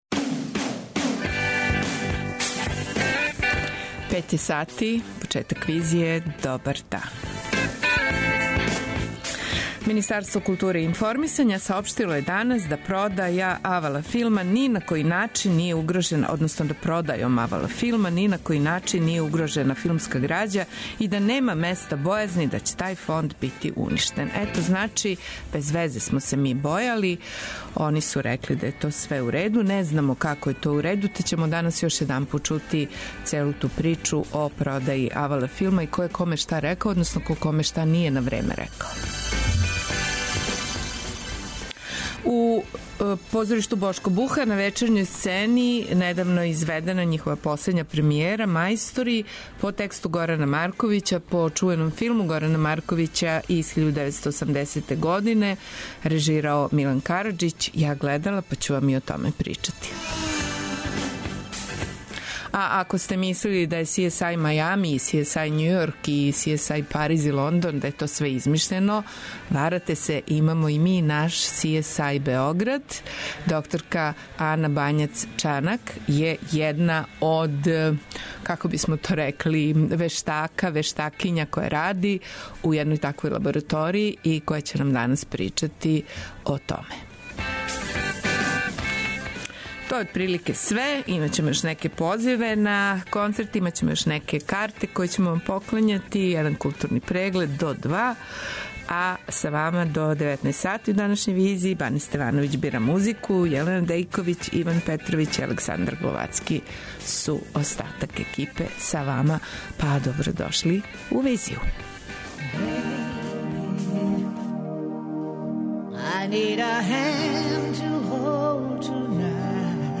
Си ес ај, али не ни Мајами ни Њујорк, већ си ес ај Београд, уживо, у другом сату данашње Визије.